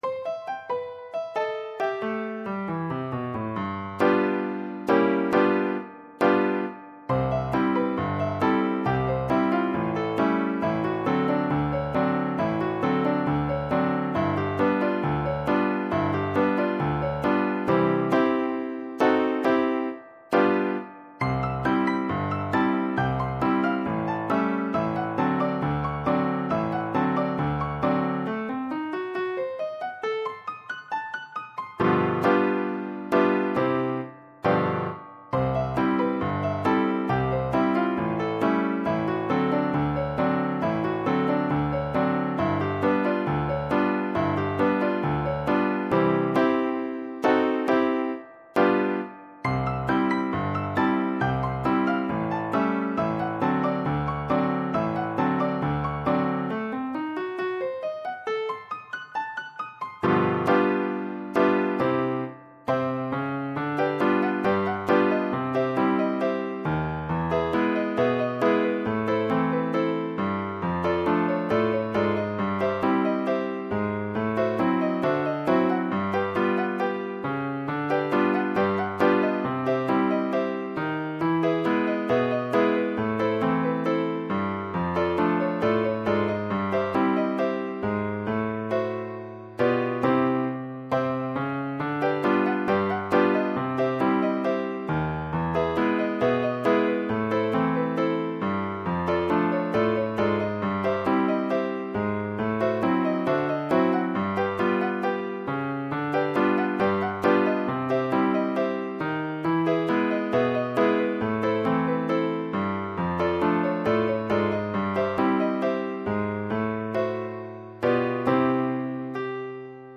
This is not a performance version, but is simply a file which lilypond produces when processing the source.
An mpeg (.mp3) file produced from the midi file.